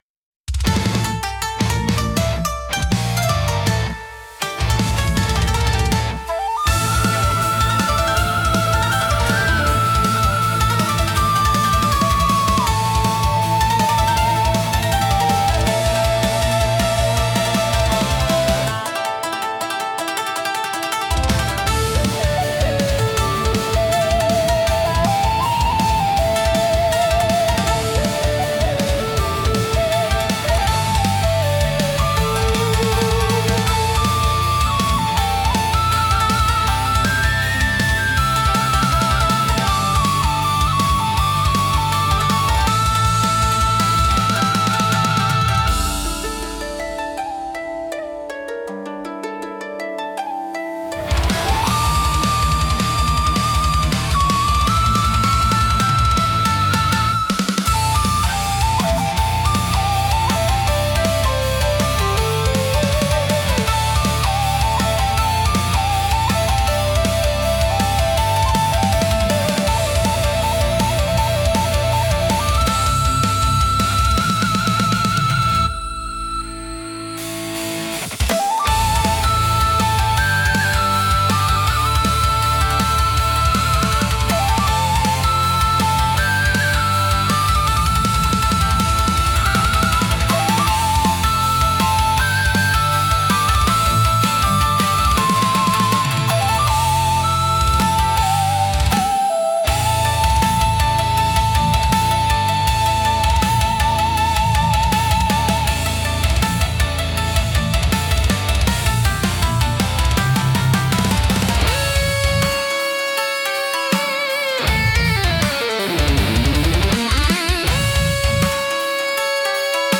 尺八の幽玄な響きと琴の繊細な調べが、重厚なギタートーンや高速ビートと絡み合い、独自の緊張感とダイナミズムを生み出します。
聴く人に力強さと神秘性を同時に感じさせ、日本古来の精神と現代のエネルギーを融合したインパクトを与えます。